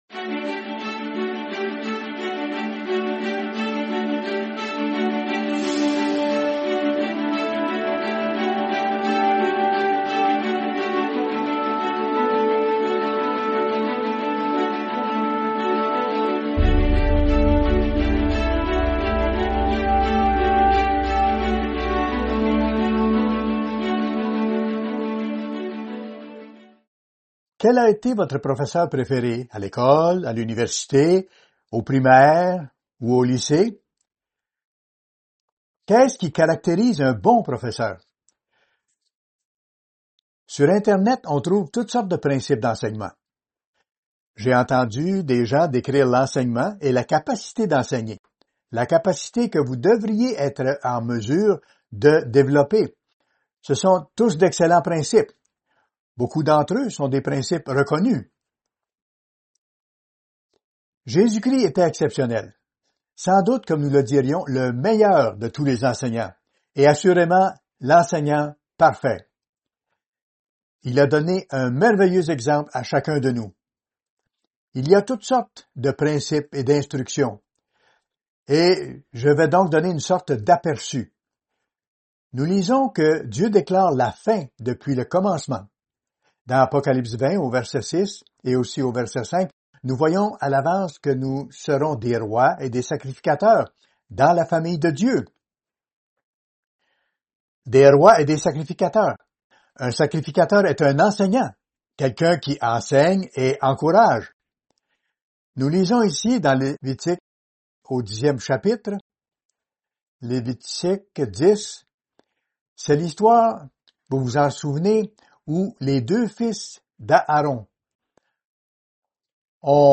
Fête des Tabernacles – 5e jour